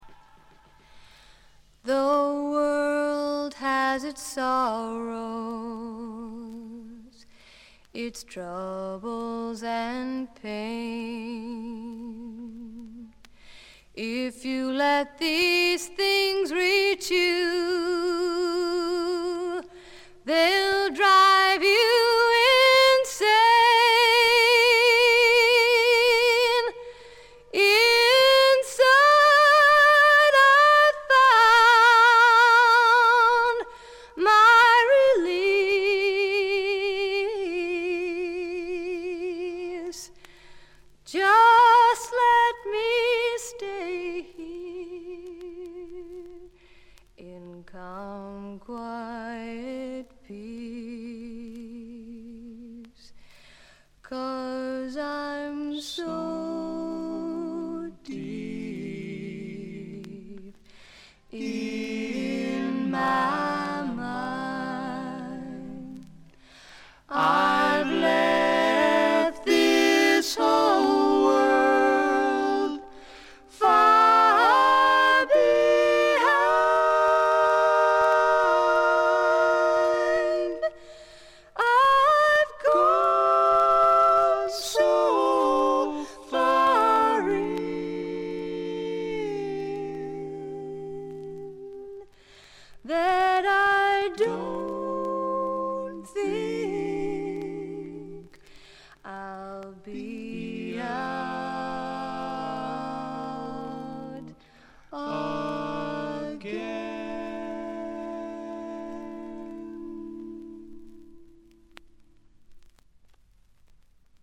ホーム > レコード：英国 SSW / フォークロック
静音部でチリプチが聞かれますが気になるノイズはありません。
試聴曲は現品からの取り込み音源です。